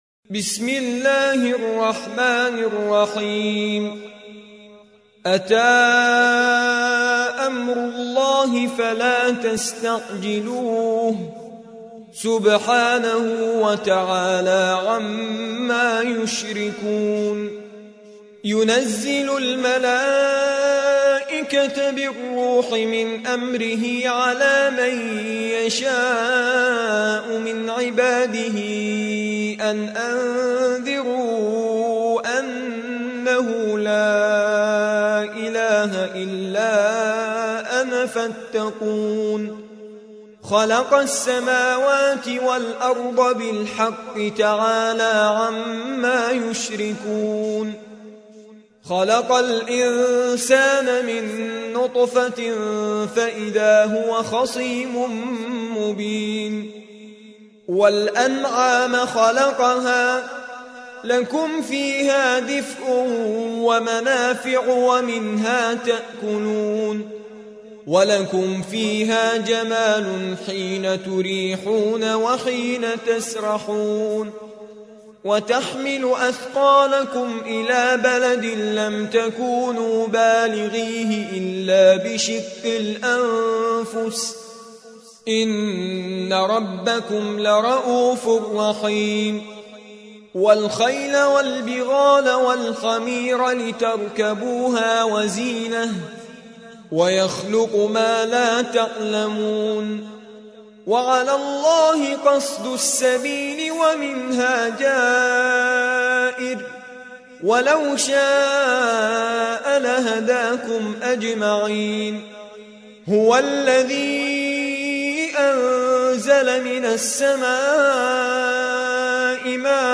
16. سورة النحل / القارئ